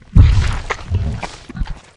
flesh_eat_0.ogg